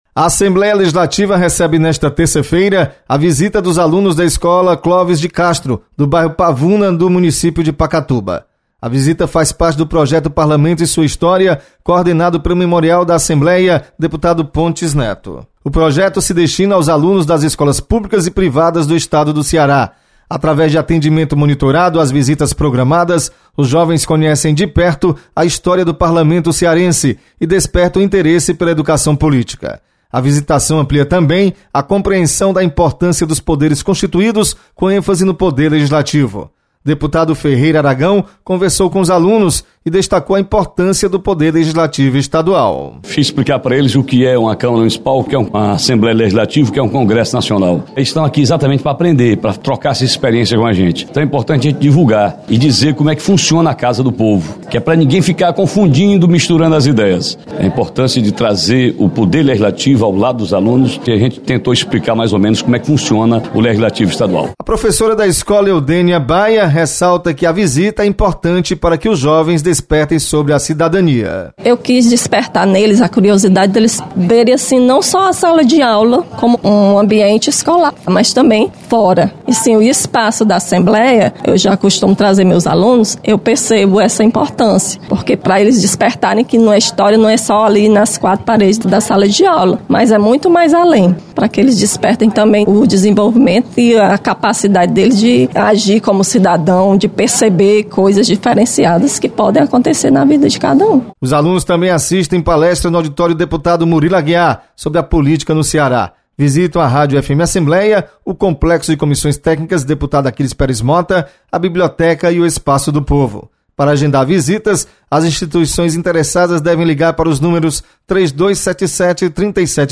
Você está aqui: Início Comunicação Rádio FM Assembleia Notícias Memorial